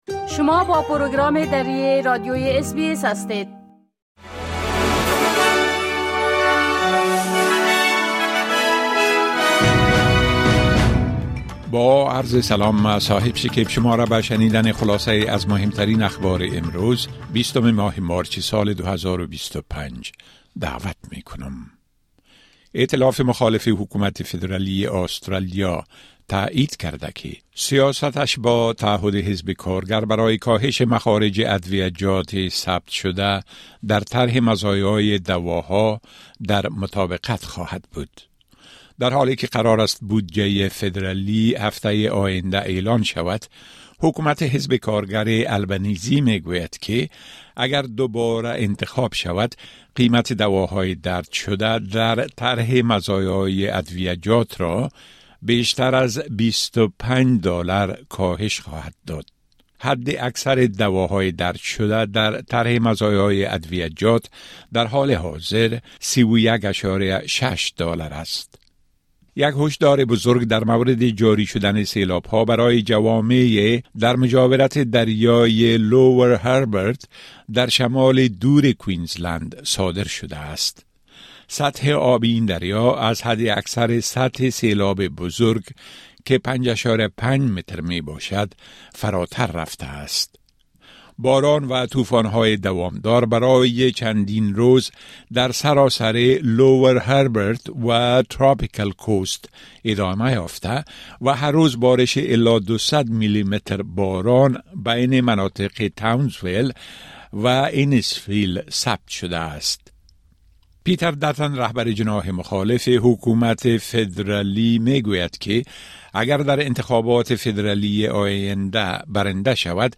خلاصۀ مهمترين اخبار روز از بخش درى راديوى اس بى اس
10 am News Update Source: SBS / SBS Filipino